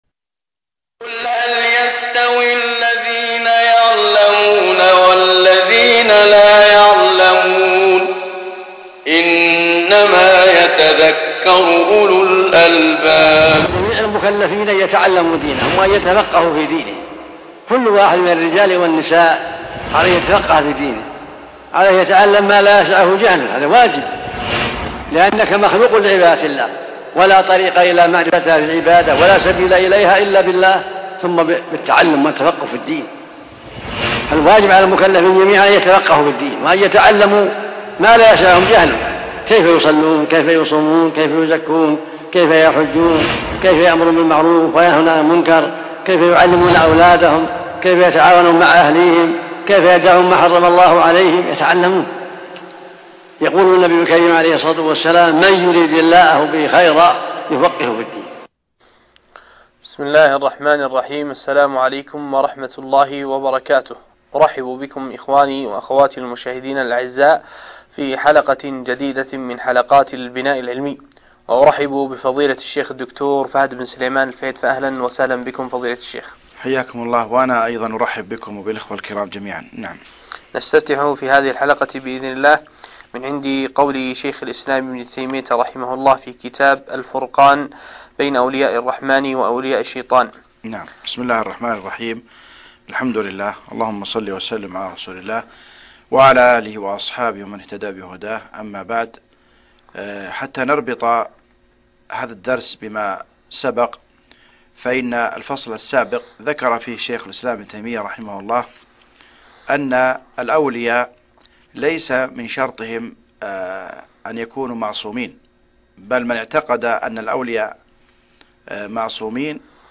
الدرس ( 11) صفات أولياء الشيطان- الفرقان بين أولياء الرحمن وأولياء الشيطان - قسم المنوعات